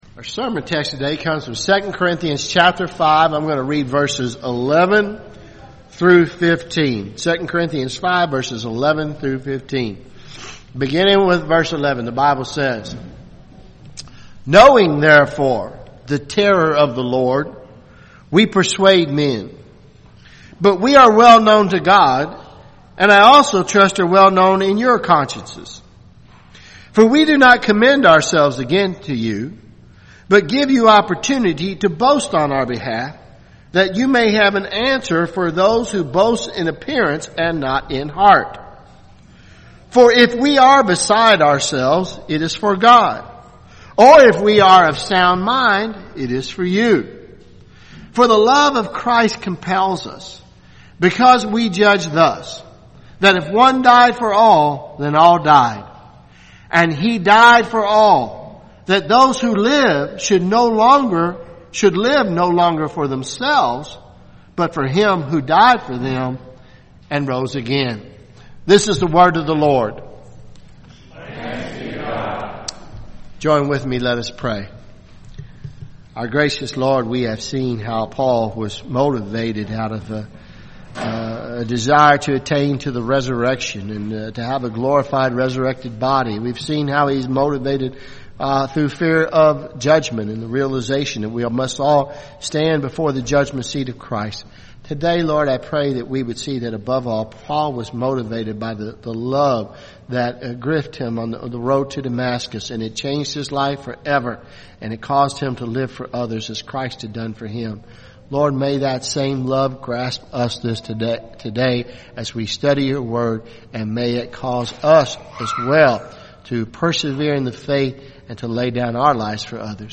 at Christ Covenant Presbyterian Church, Lexington, Ky.